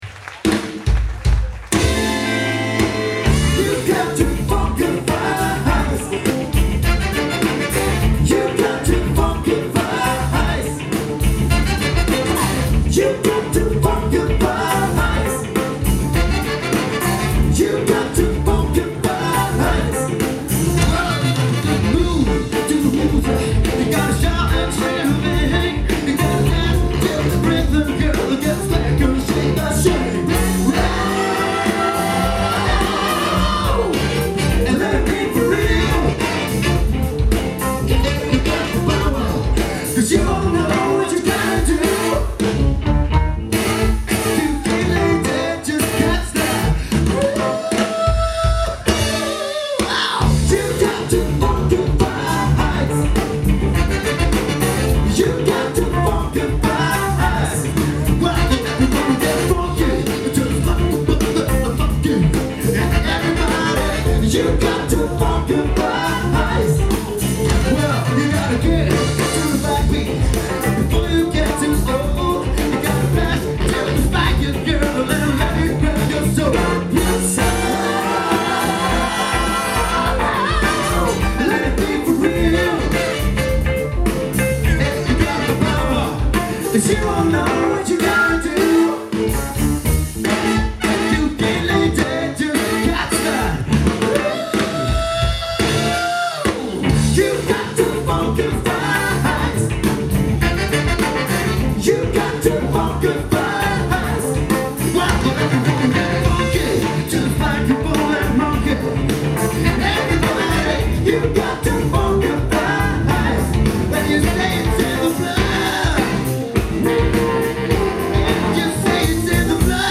Utdrag fra konsert, News,Trondheim 30.08.2001